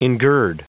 Prononciation audio / Fichier audio de ENGIRD en anglais
Prononciation du mot : engird